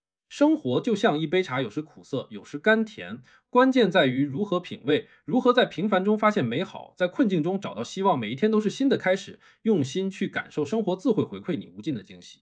MultiSpeaker_v0 / f5-tts /11.wav